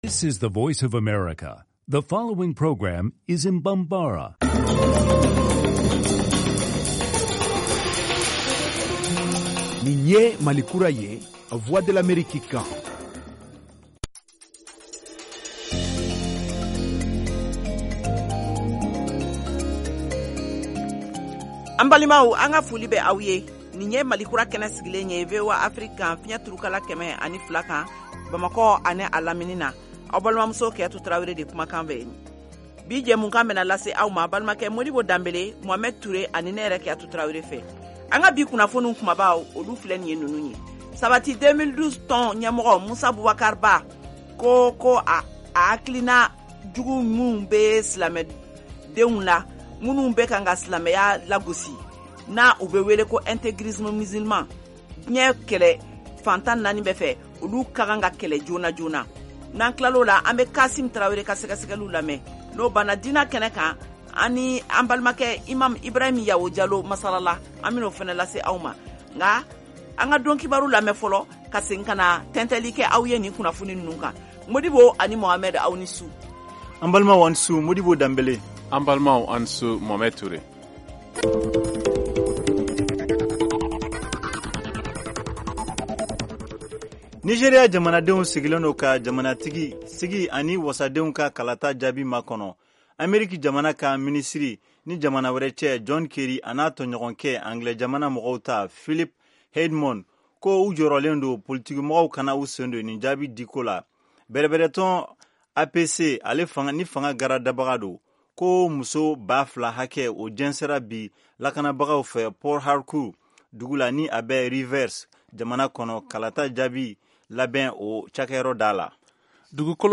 Emission quotidienne en langue bambara
en direct de Washington, DC, aux USA.